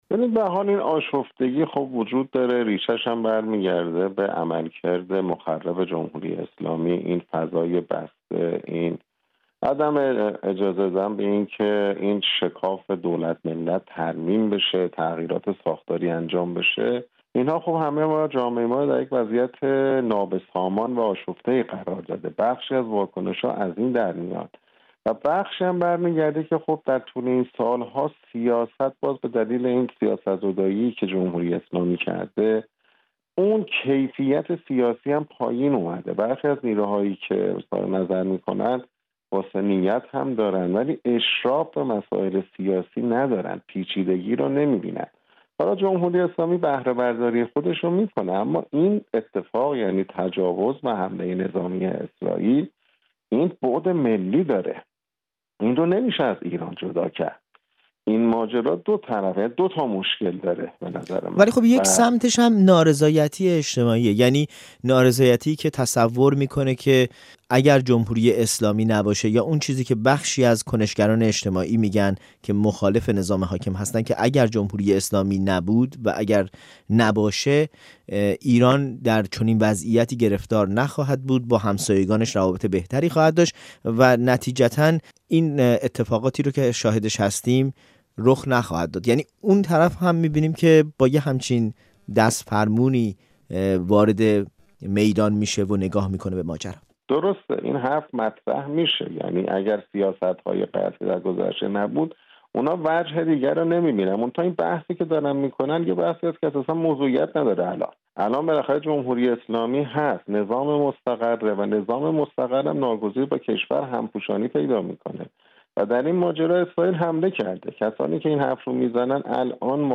در گفت‌وگو با رادیو فردا